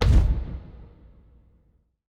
Fantasy Interface Sounds
Special Click 02.wav